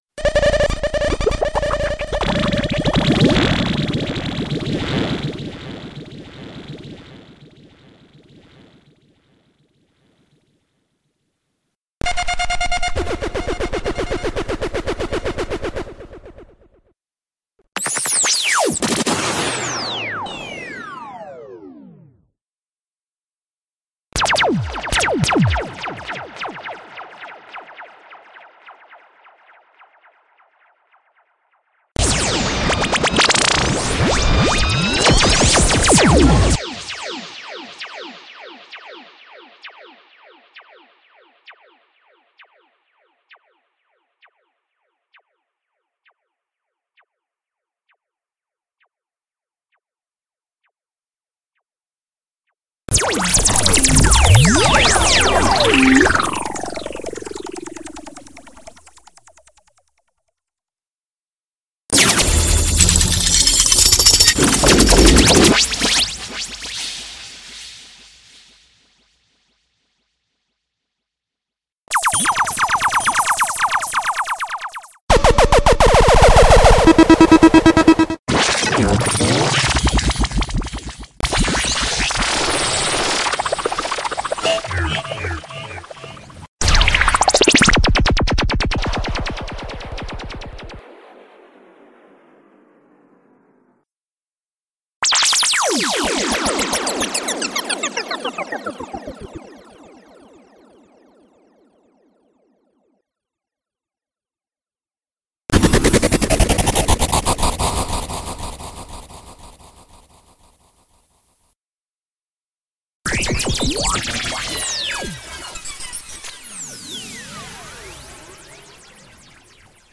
SOUND EFFECTS PACK 27